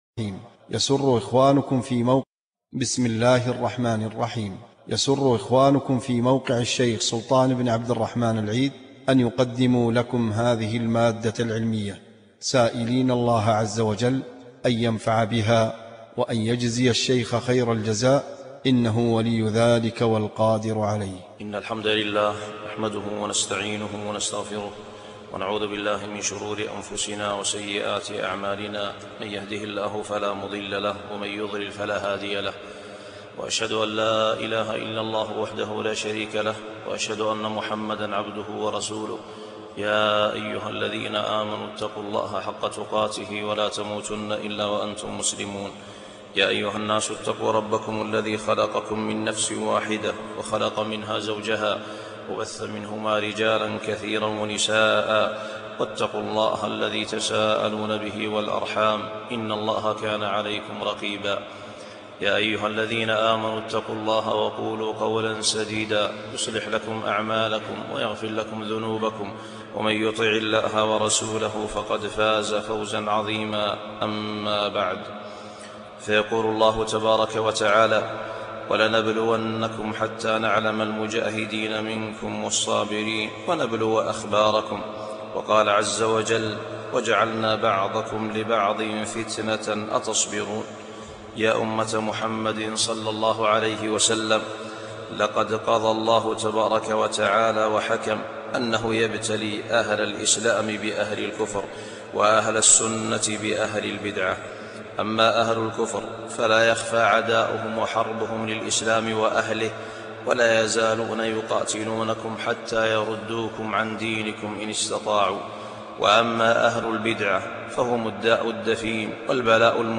خطبة - اعتقاد أهل الأثر في المهدي المنتظر